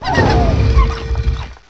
sovereignx/sound/direct_sound_samples/cries/tinkaton.aif at eccd2c4a659cd472a3ea583b4d1ac750e60a59ce